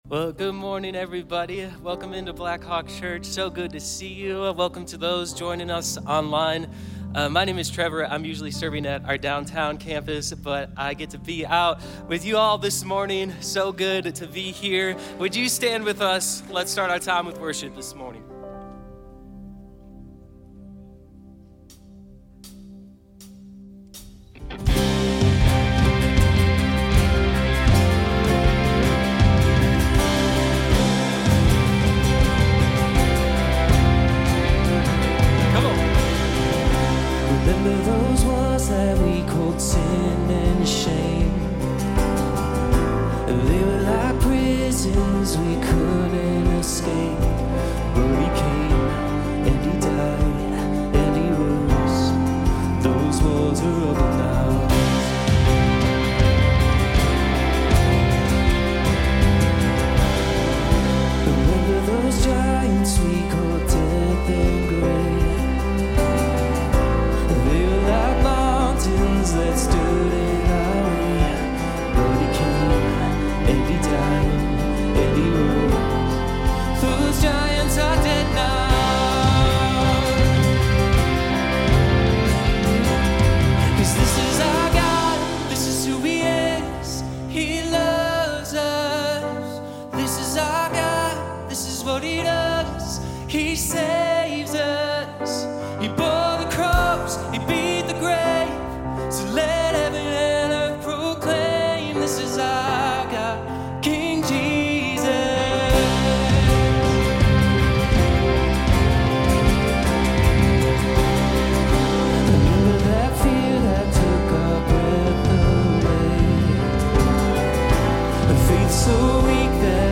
A message from the series "Once Upon a Future."